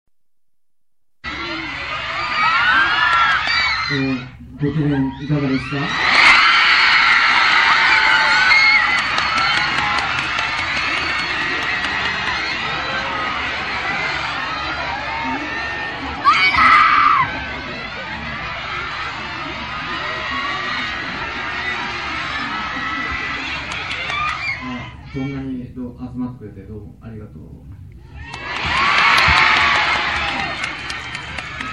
Mana-sama....говорит..у меня от его голоса мурашки по телу...в хорошем смысле...и вообще это огромная редкость..так как он почти не разговаривает...